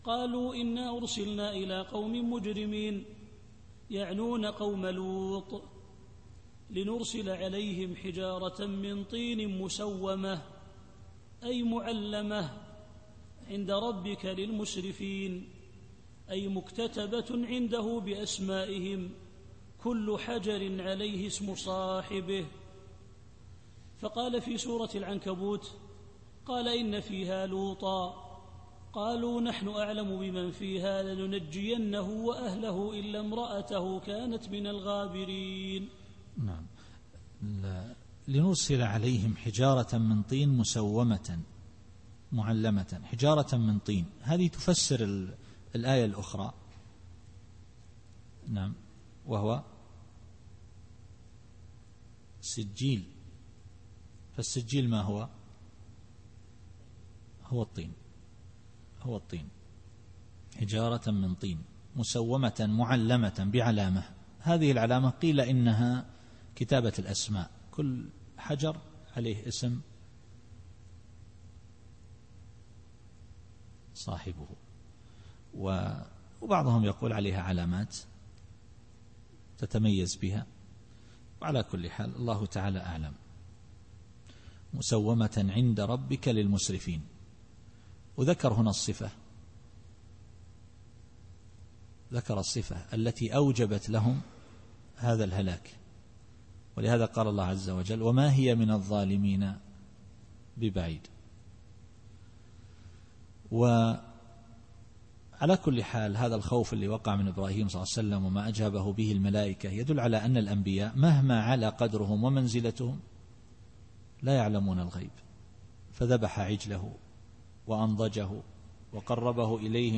التفسير الصوتي [الذاريات / 32]